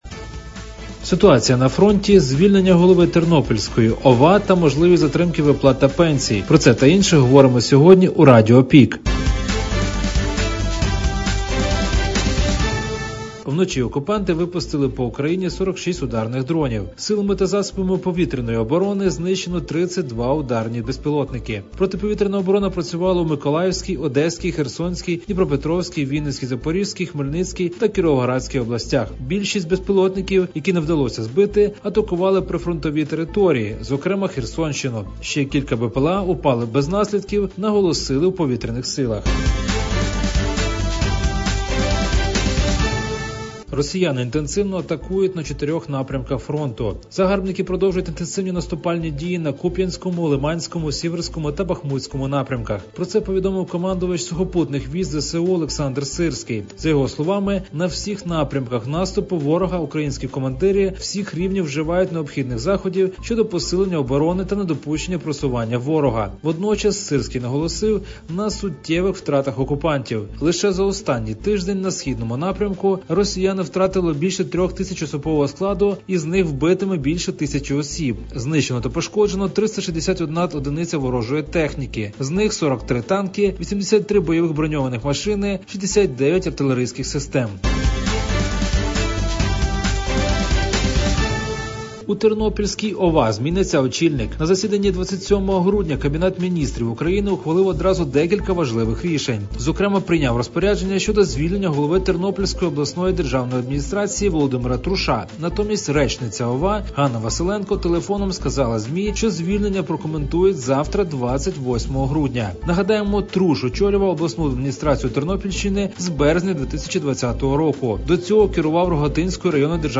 Радіо ПІК: головні новини Прикарпаття та України за 27 грудня (ПРОСЛУХАТИ)